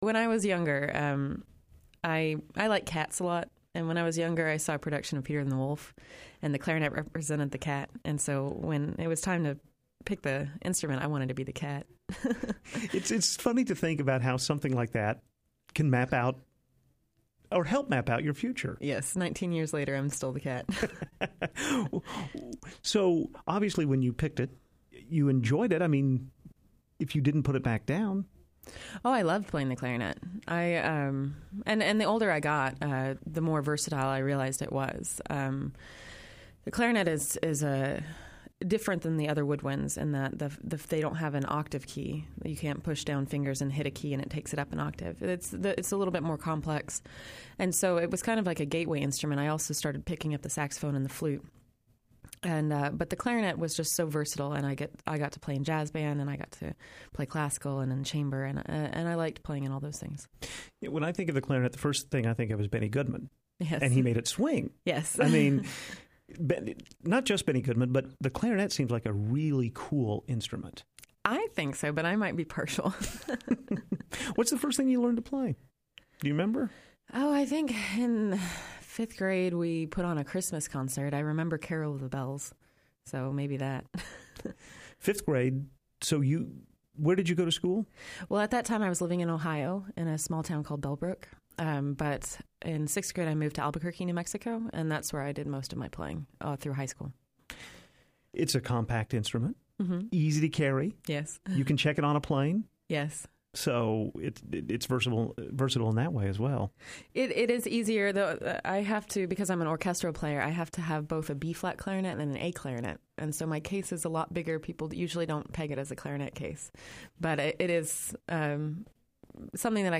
Clarinetist Visits the University of Arkansas